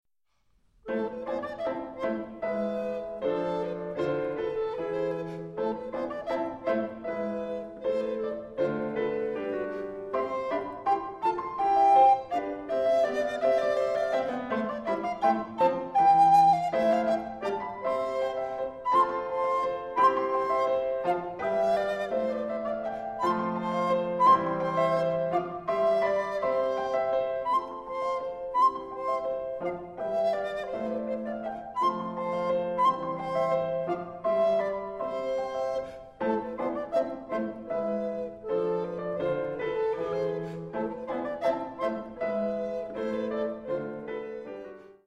Obsazení: Alt-Blockflöte und Spinett (Klavier)